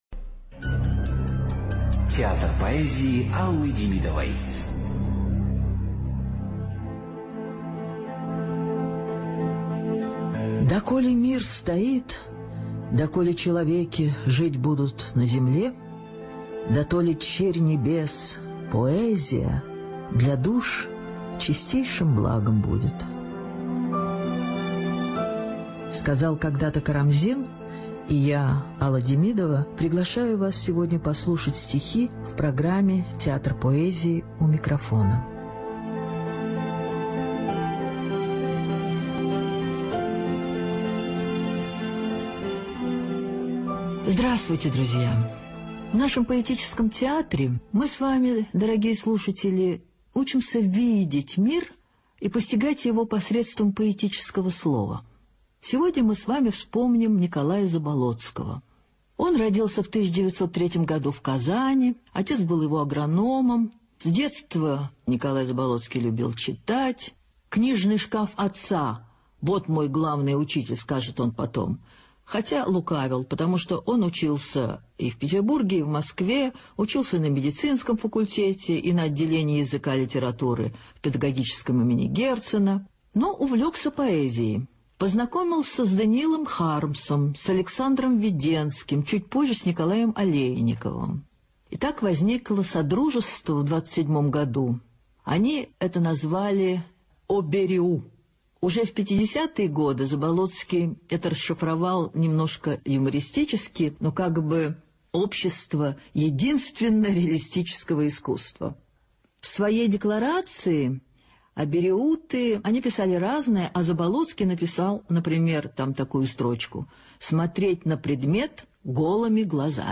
ЧитаетДемидова А.
ЖанрПоэзия